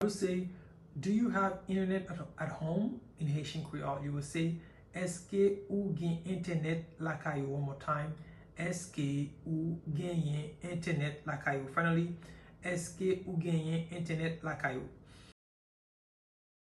Do you have internet at home?” pronunciation in Haitian Creole by a native Haitian can be learned and watched in the video below too:
Do-you-have-Internet-at-home-in-Haitian-Creole-Eske-ou-gen-entenet-lakay-ou-pronunciation-by-a-Haitian-teacher.mp3